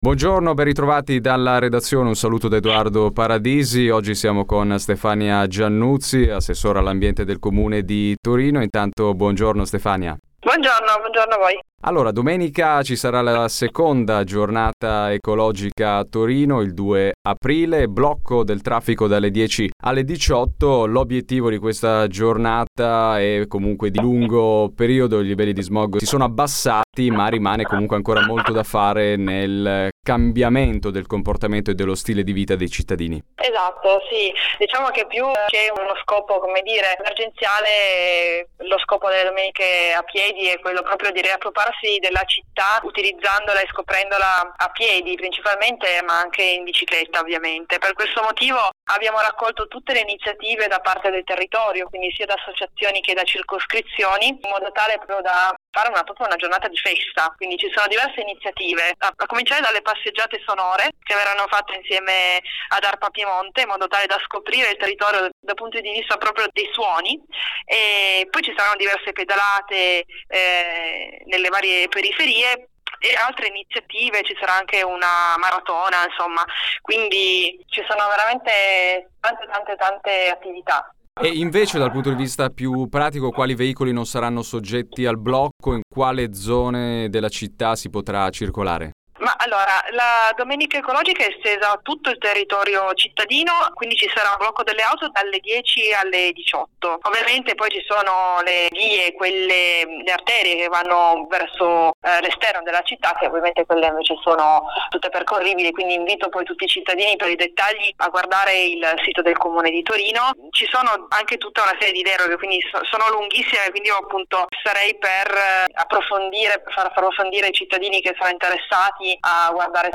Abbiamo parlato della domenica ecologica e delle iniziative con l’Assessora all’Ambiente Stefania Giannuzzi. Di seguito l’intervista radiofonica completa.